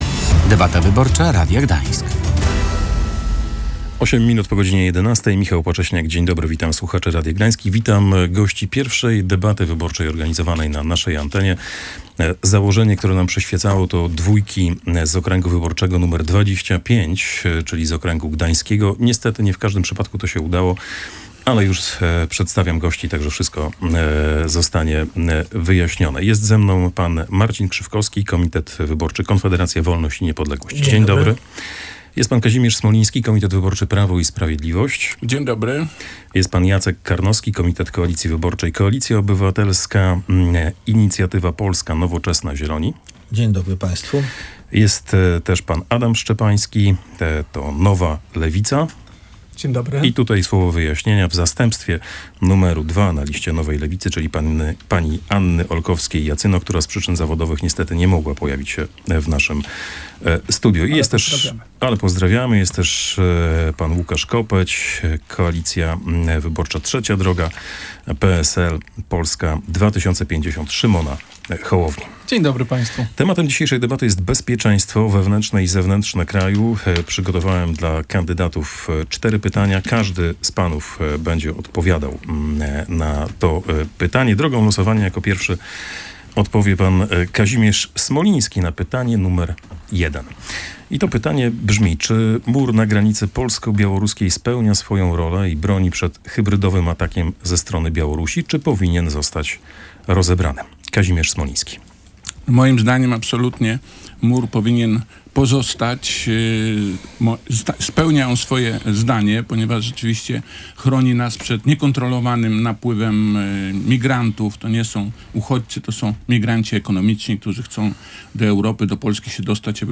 Pierwsza debata przedwyborcza w Radiu Gdańsk. Kandydaci dyskutowali o bezpieczeństwie Polski